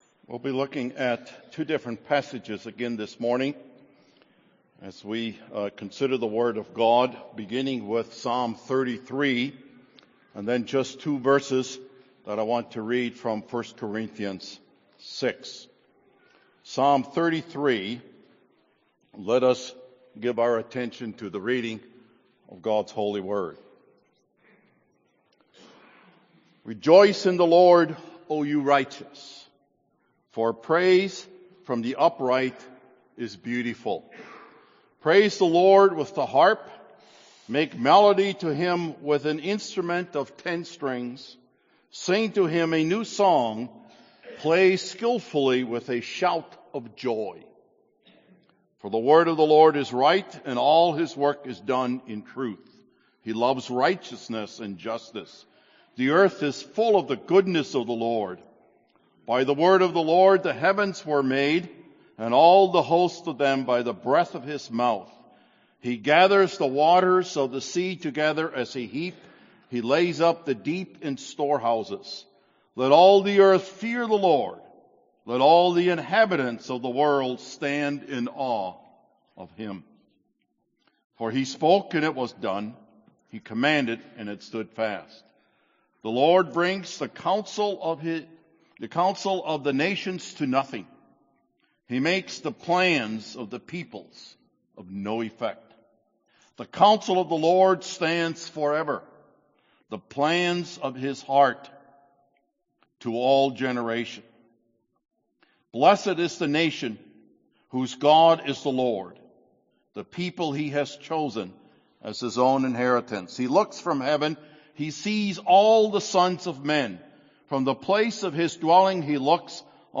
That I -- Am Not My Own | SermonAudio Broadcaster is Live View the Live Stream Share this sermon Disabled by adblocker Copy URL Copied!